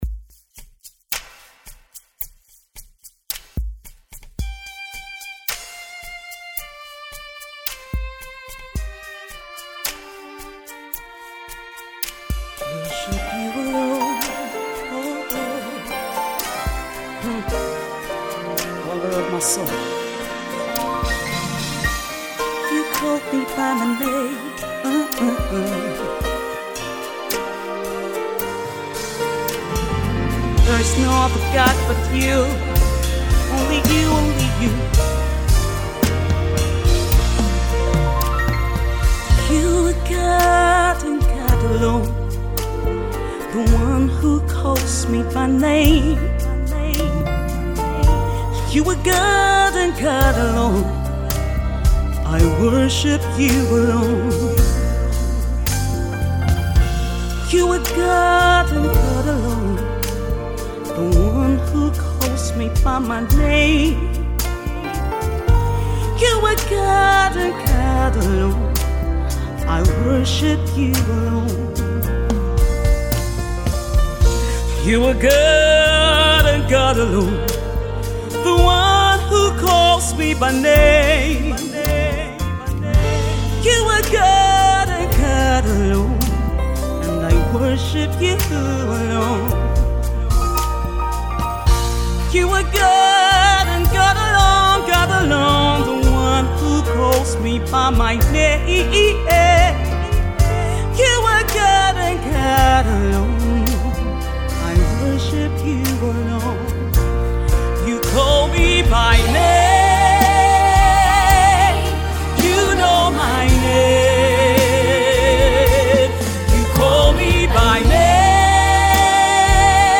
Aside from being a gospel music minister